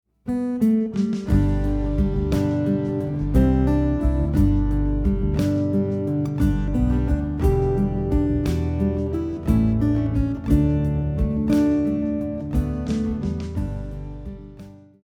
guitar arrangement preview